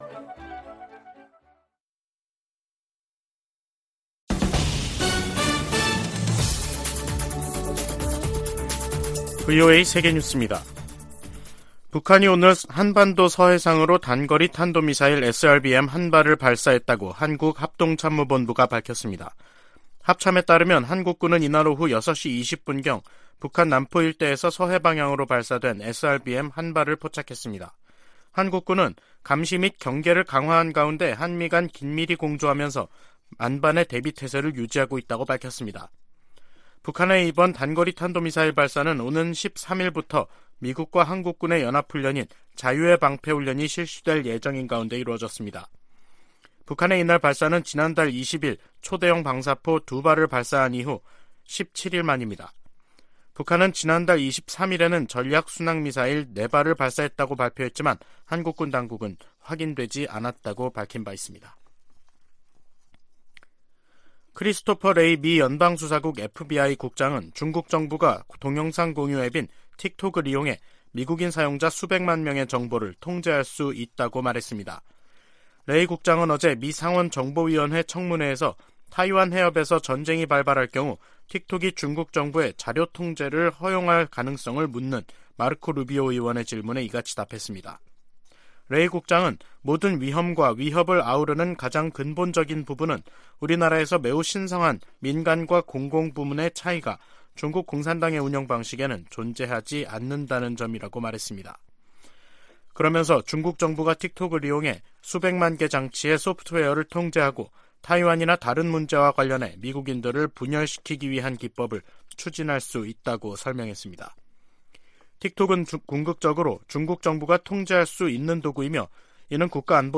VOA 한국어 간판 뉴스 프로그램 '뉴스 투데이', 2023년 3월 9일 2부 방송입니다. 윤석열 한국 대통령이 오는 16일 일본을 방문해 기시다 후미오 총리와 정상회담을 갖는다고 한국 대통령실이 밝혔습니다. 미 국무부는 미한일 3자 확장억제협의체 창설론에 대한 입장을 묻는 질문에 두 동맹국과의 공약이 철통같다고 밝혔습니다. 권영세 한국 통일부 장관은 미래에 기초한 정책을 북한 지도부에 촉구했습니다.